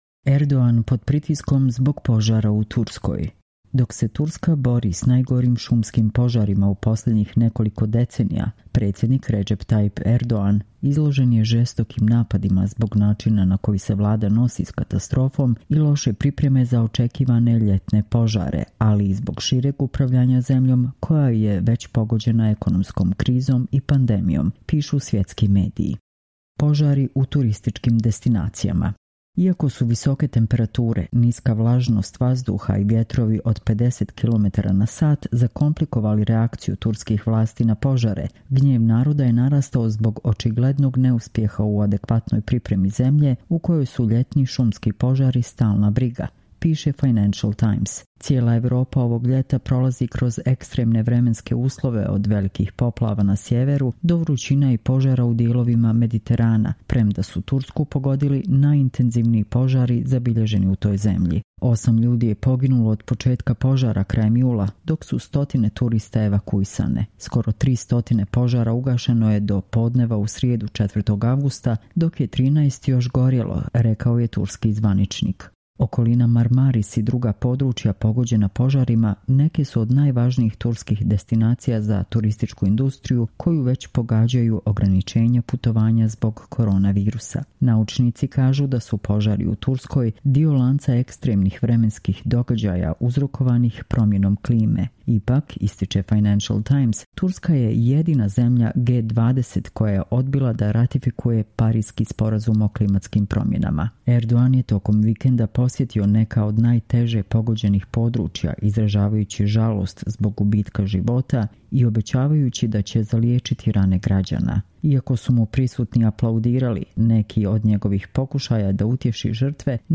Čitamo vam: Erdogan pod pritiskom zbog požara u Turskoj